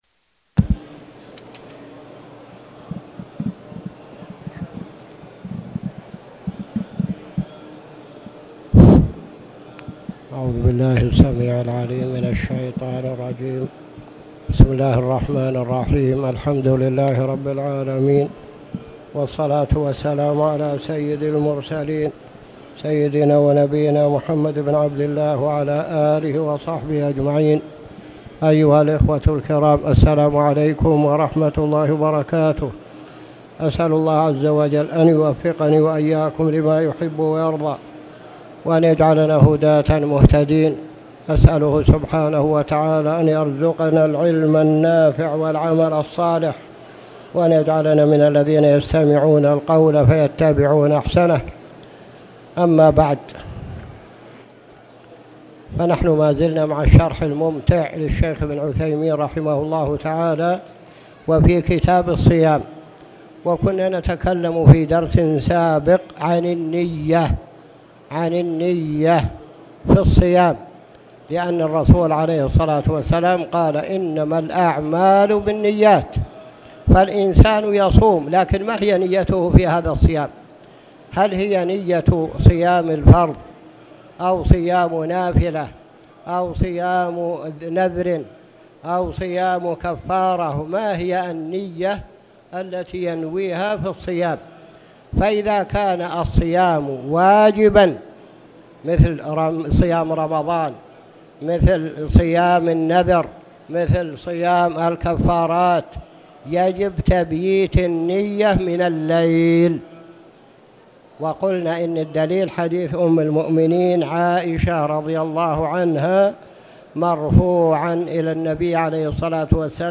تاريخ النشر ٢٥ رجب ١٤٣٨ هـ المكان: المسجد الحرام الشيخ